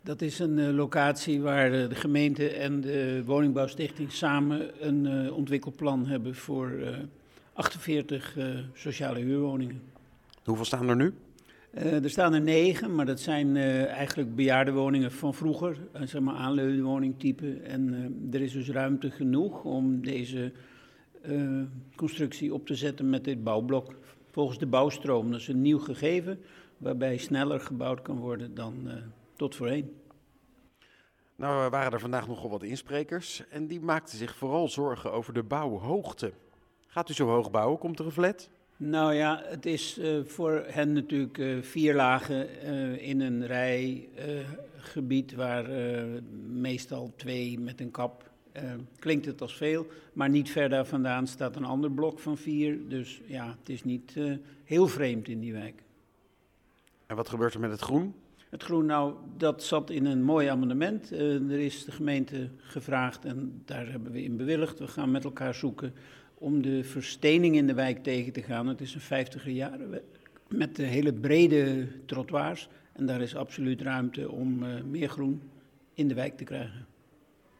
Wethouder Ritske Bloemendaal over de herontwikkeling van de Van Rechterenstraat in Wassenaar: